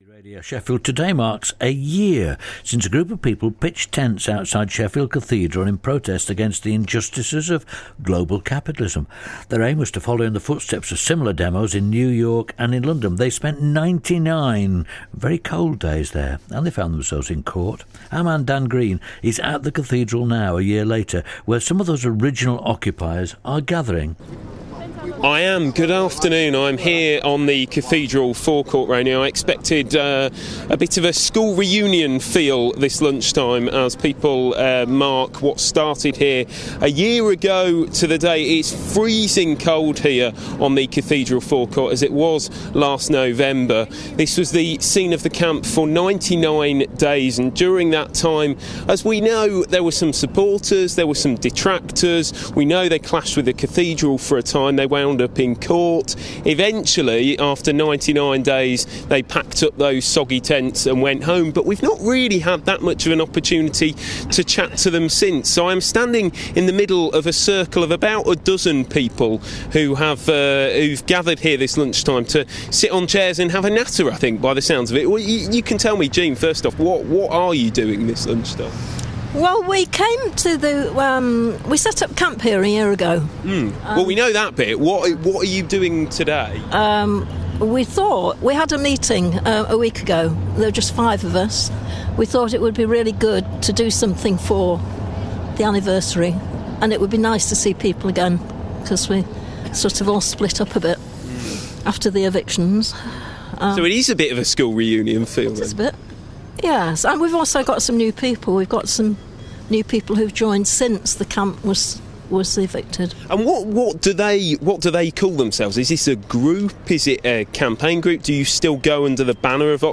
A group of people gathered outside Sheffield Cathedral to mark a year since the Occupy Sheffield protest camp started...The protest against the injustices of global capitalism followed similar demonstrations in New York and London...